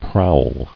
[prowl]